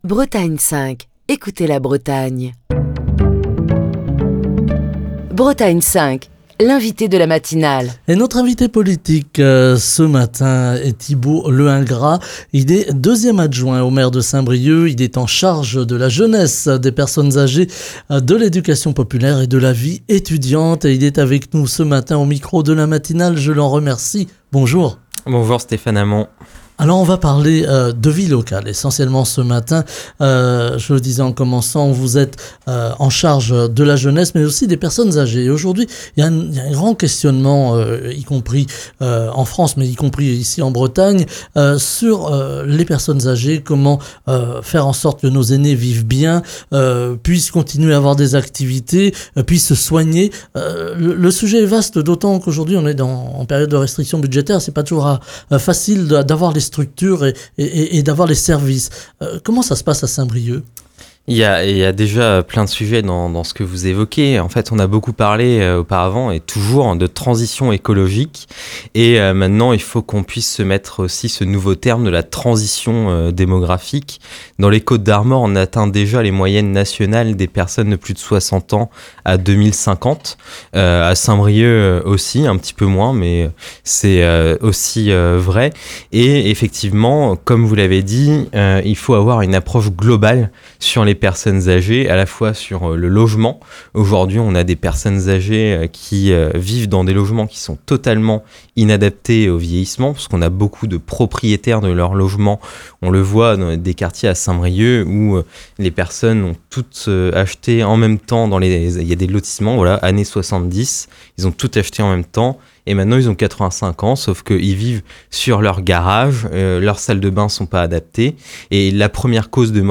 Thibaut Le Hingrat, deuxième adjoint au maire de Saint-Brieuc, en charge de la jeunesse, des personnes âgées, de l'éducation populaire et de la vie étudiante, était l'invité politique de la matinale de Bretagne 5, ce mercredi.